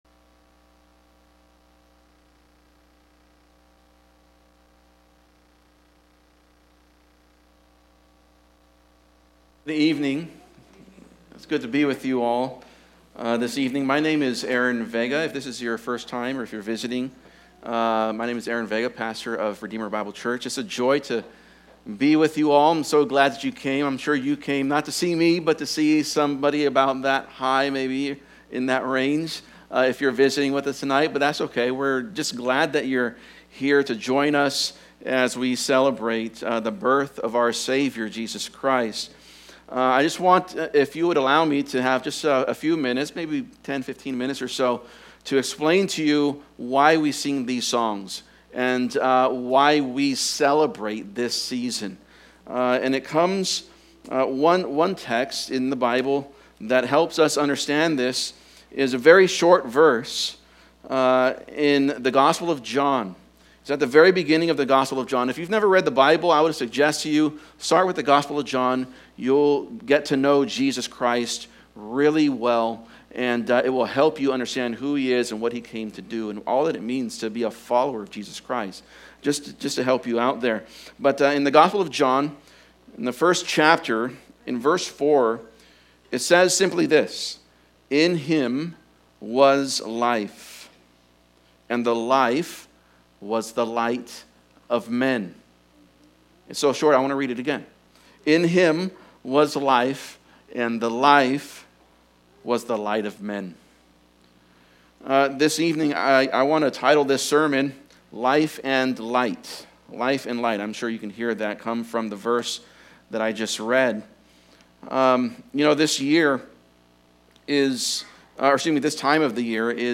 Christmas Hymn Sing Service 2024 | Life & Light | Redeemer Bible Church